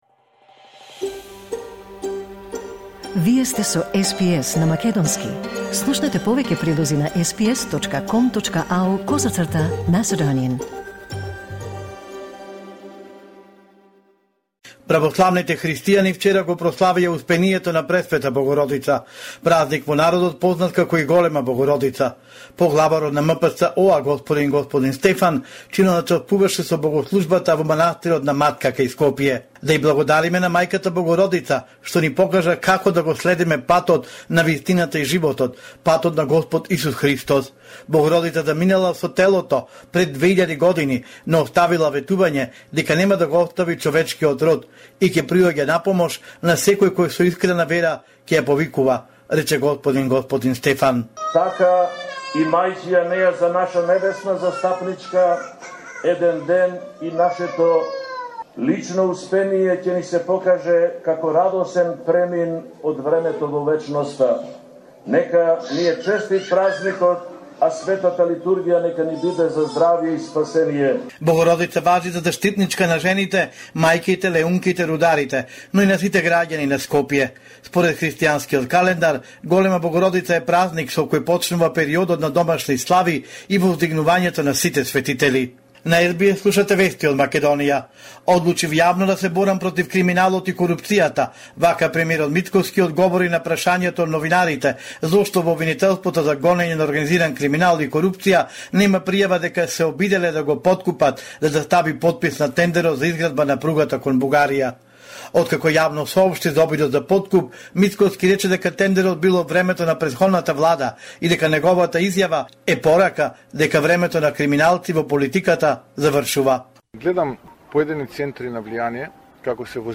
Homeland Report in Macedonian 29 August 2024